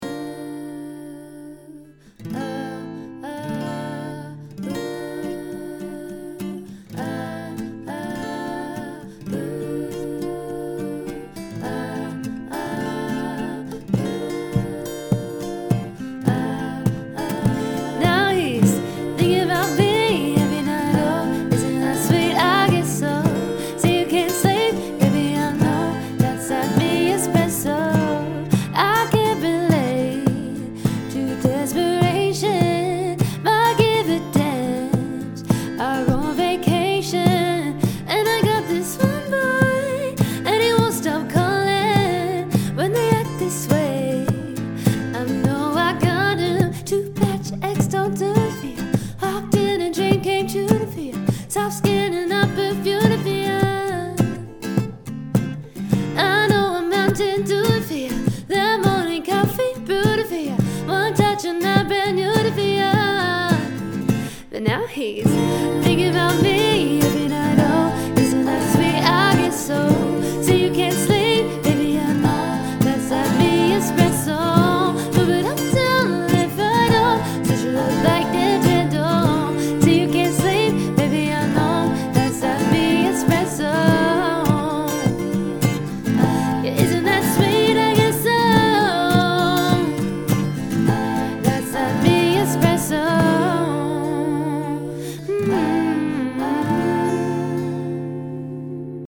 Vocals | Looping